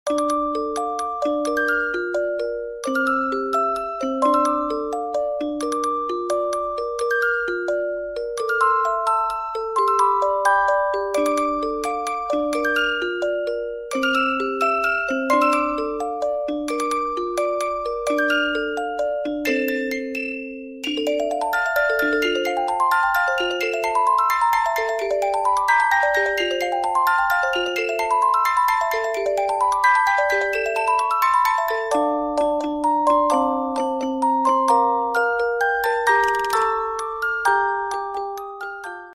Спокойные И Тихие Рингтоны » # Рингтоны Без Слов